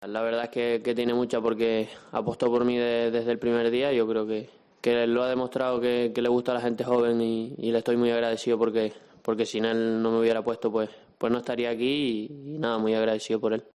El centrocampista del Barcelona ha atendido a los medios de comunicación desde la concentración de la Selección en la Ciudad del Fútbol de Las Rozas.